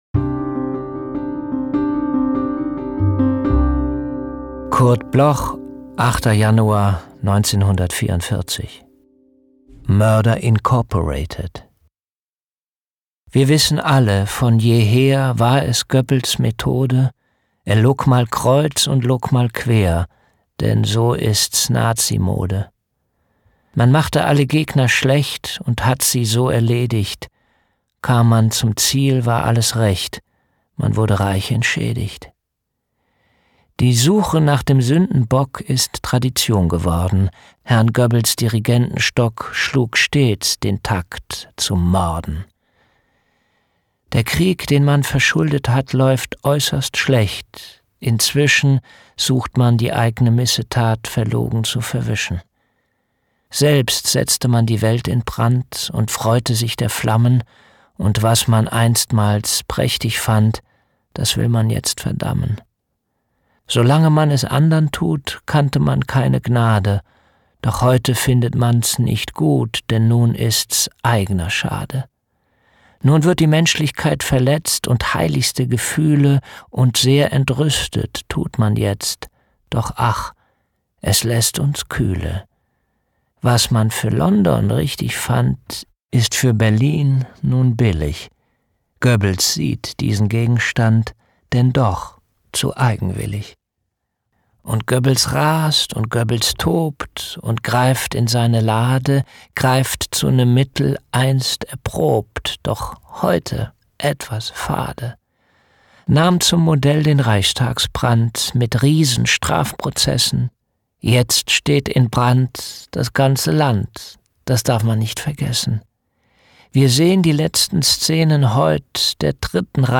Recording, Editing, and Music: Kristen & Schmidt, Wiesbaden
Jens-Harzer-MURDER-INC-mit-Musik_raw.mp3